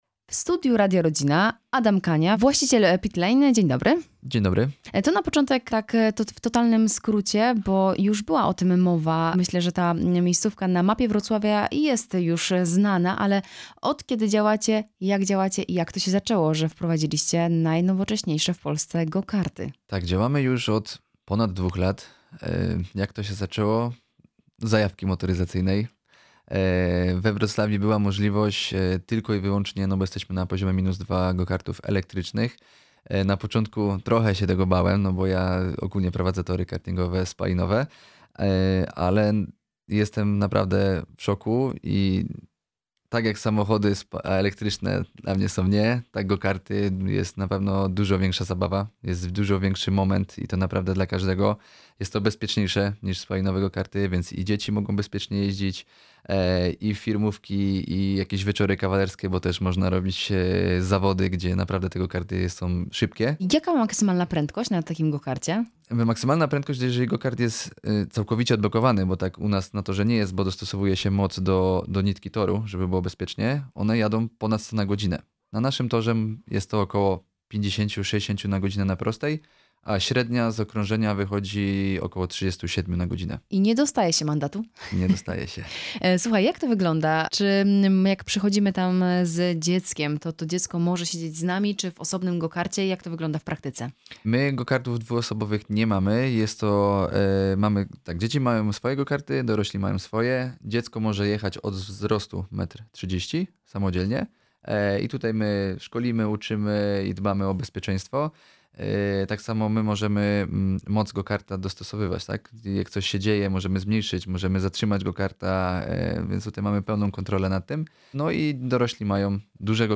CAŁA ROZMOWA: